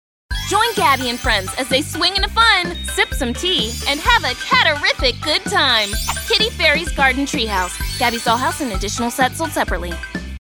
Commercial & problem Corporate Work
Kitty Fairy Treehouse — Kids, Enthusiastic, Friendly
Commercial-Kitty-Fairys-Garden-Tree-House.mp3